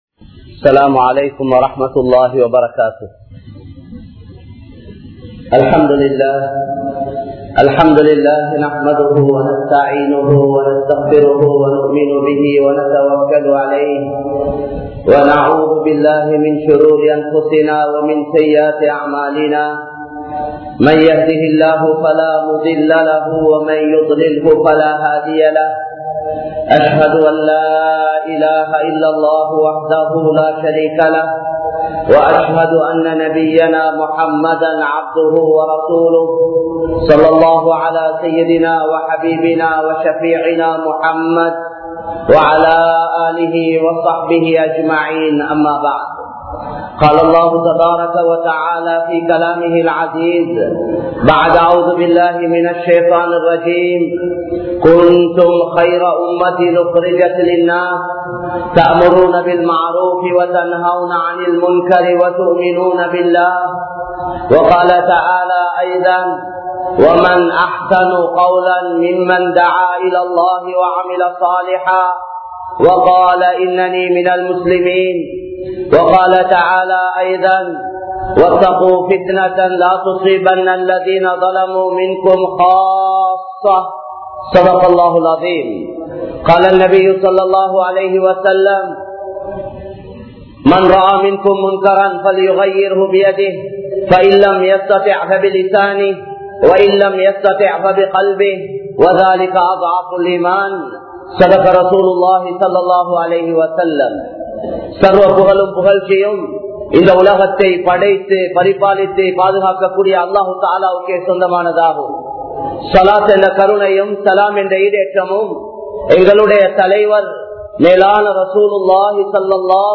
Dawath Illaamal Enna Vaalkai?(தஃவத் இல்லாமல் என்ன வாழ்க்கை?) | Audio Bayans | All Ceylon Muslim Youth Community | Addalaichenai